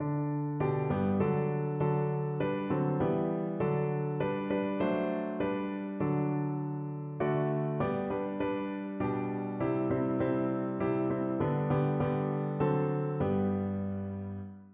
No parts available for this pieces as it is for solo piano.
3/4 (View more 3/4 Music)
Piano  (View more Easy Piano Music)
Classical (View more Classical Piano Music)